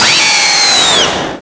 pokeemerald / sound / direct_sound_samples / cries / zebstrika.aif